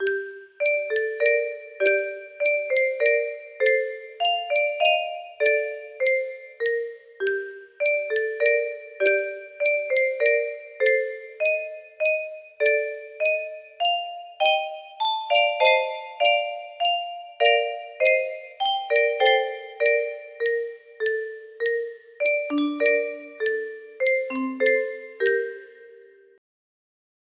Luluby music box melody